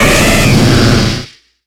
Cri d'Aligatueur dans Pokémon X et Y.